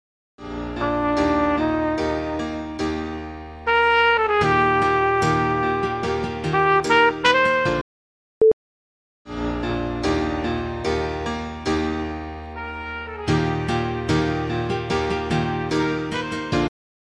Trumpet recording quality
There is stereo reverb effect used on the solo trumpet, if you invert one of the tracks you can hear it …